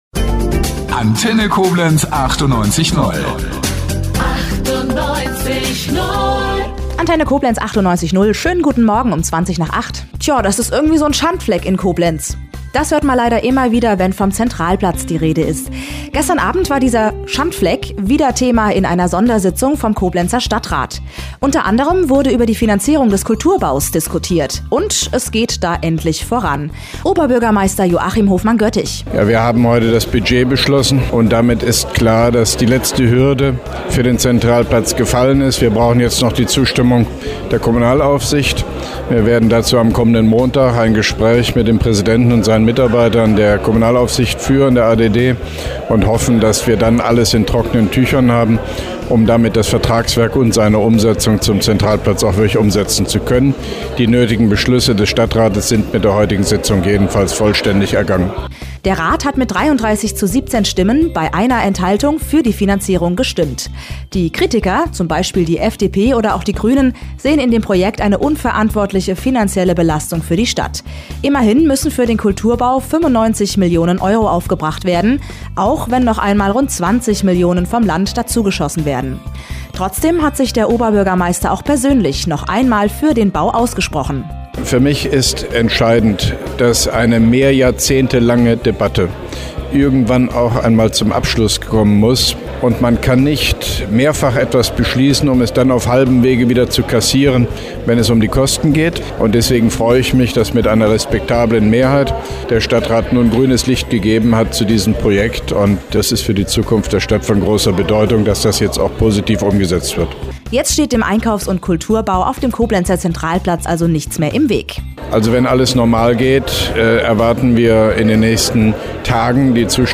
Mit Interview des Koblenzer OB Hofmann-Göttig